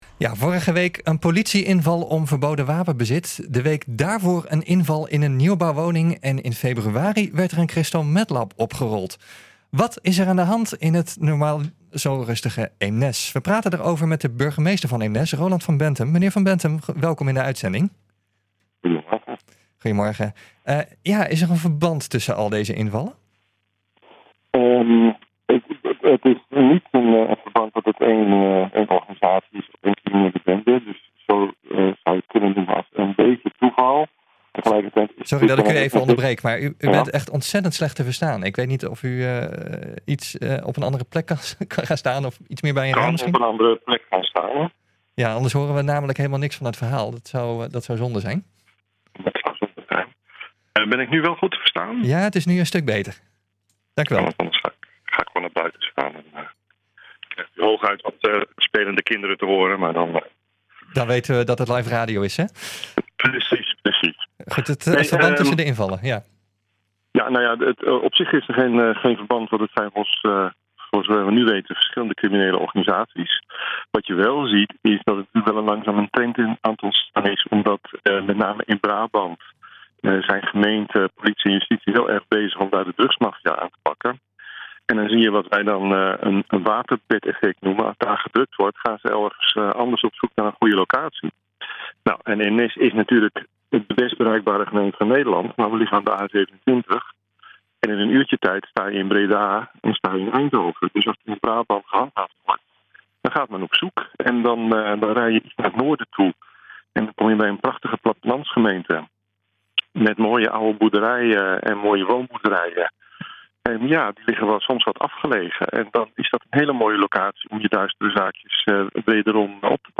Eemnes blijkt één van die plekken te zijn", vertelt de burgemeester op NH Gooi Radio
Wat is er aan de hand in het normaliter zo rustige Eemnes? We praten erover met de burgemeester van Eemnes, Roland van Benthem.